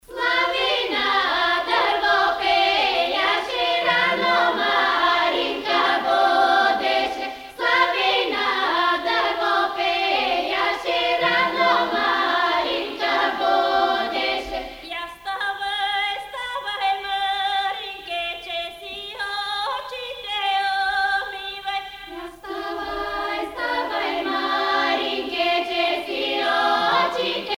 Polyphonie féminine n°5